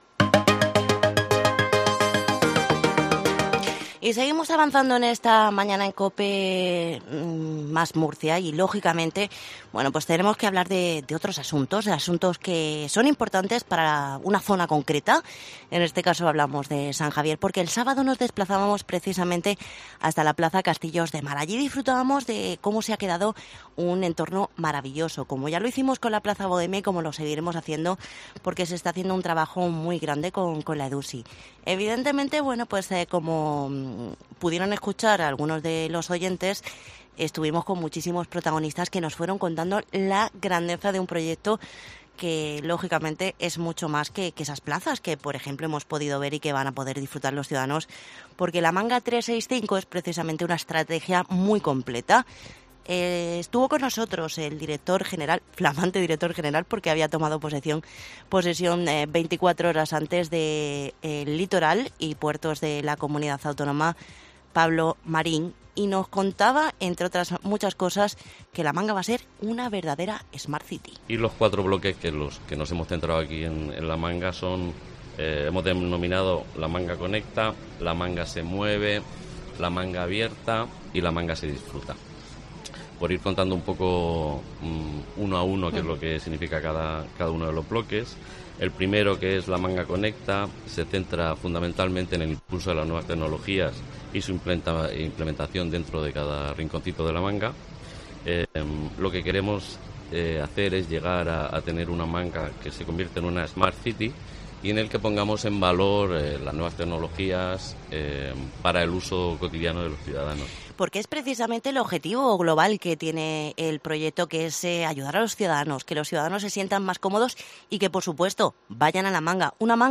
PROGRAMA ESPECIAL
Este sábado lo hizo desde la Plaza Castillos de Mar en la zona de San Javier.